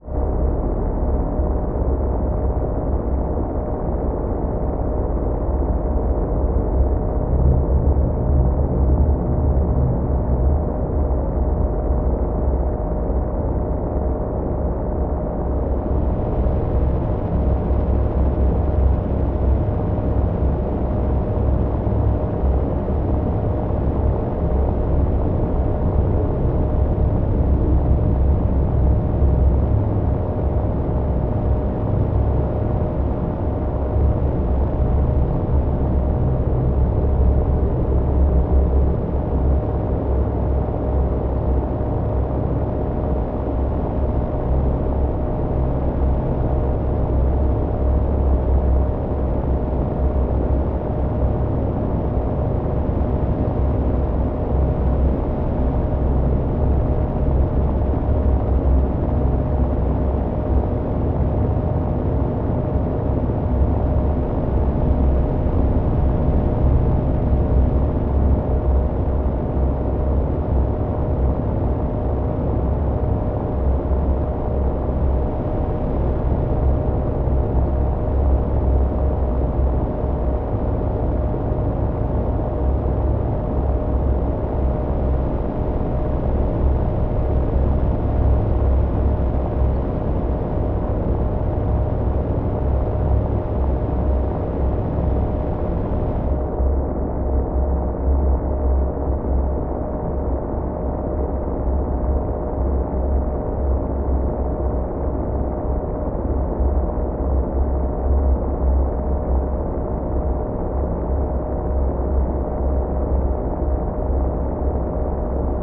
free horror ambience 2